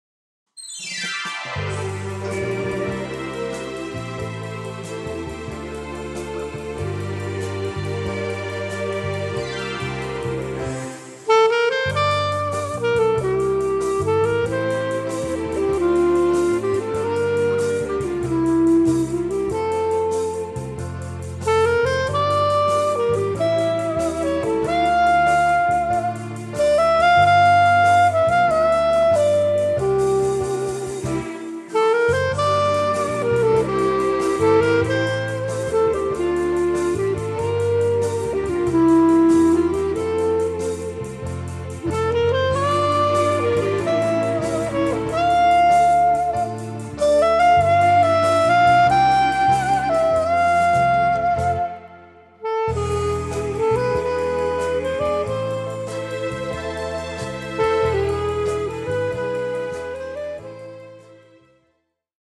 (Intermediate Level: 4-5th Grade AMEB)